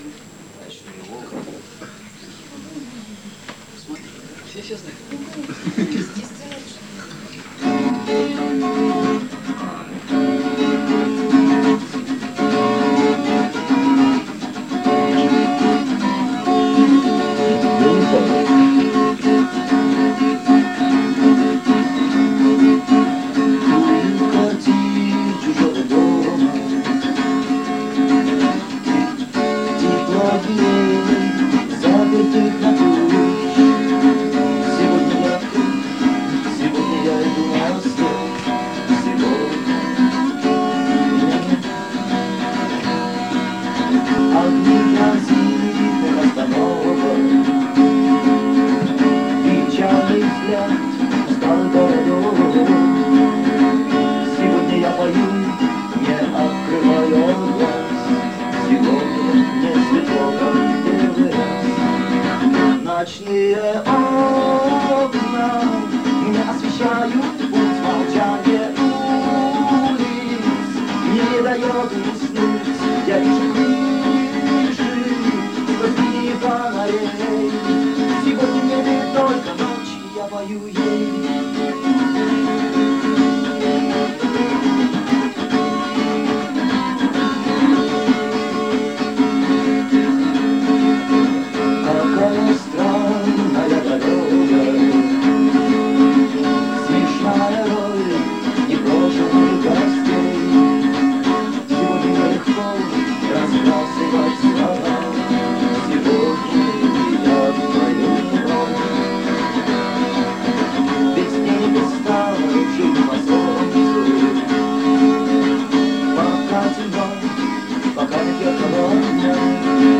с квартирника в Москве 1987 года